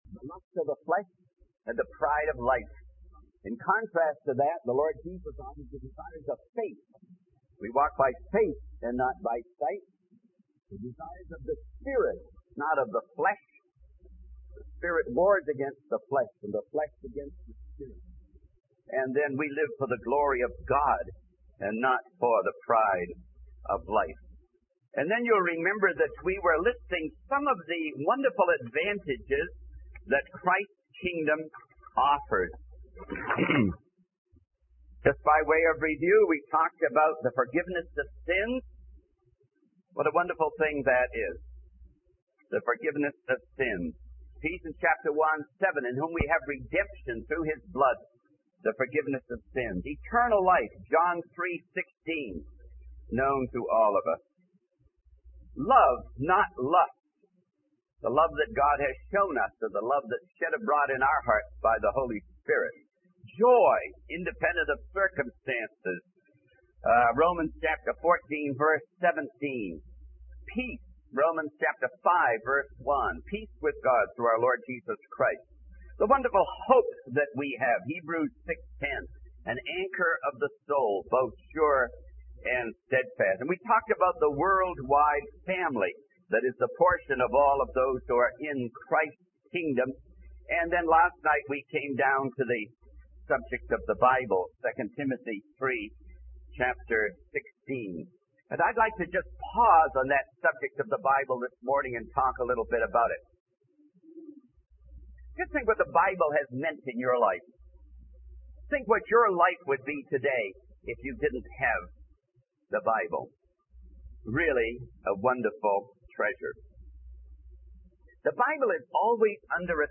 In this sermon, the speaker emphasizes the importance of the Bible in our lives and the attacks it faces. He explains that we must believe in the Bible first and then we will see its truth.